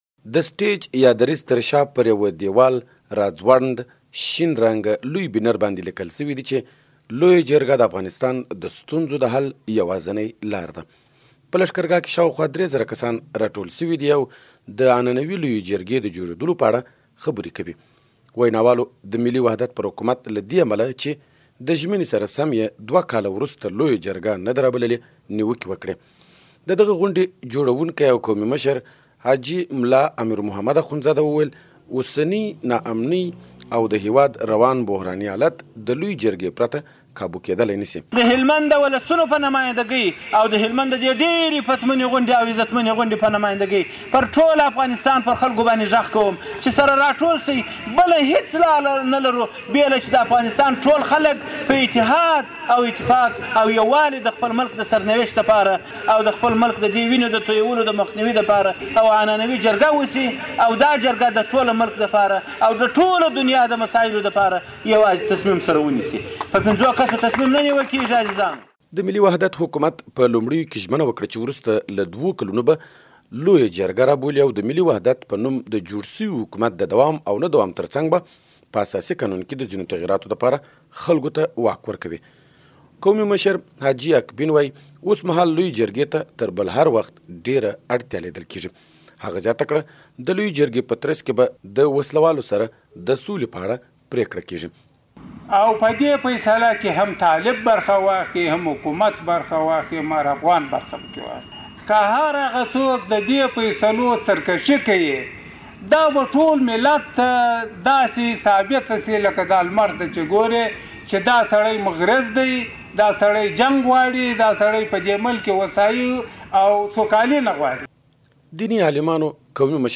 by ازادي راډیو